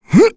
Dakaris-Vox_Jump.wav